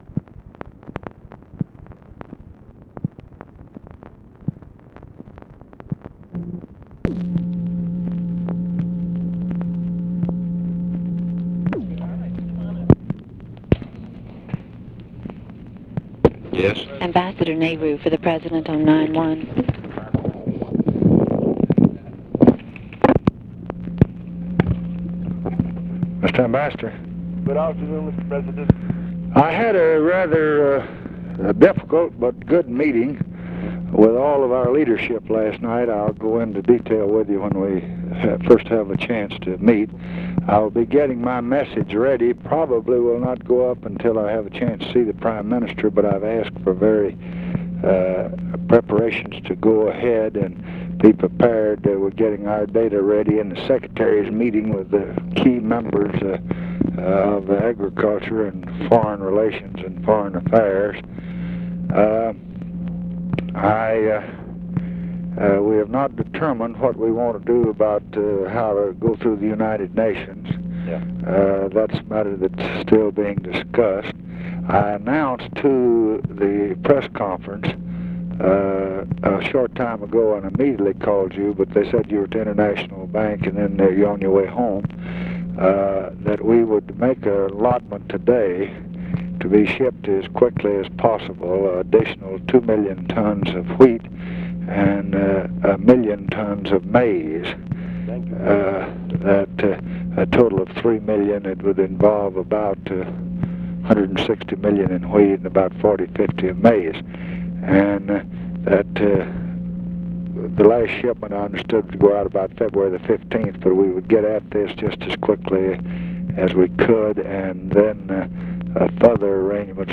Conversation with B. K. NEHRU and BILL MOYERS, February 4, 1966
Secret White House Tapes